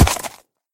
sounds / mob / horse / land.mp3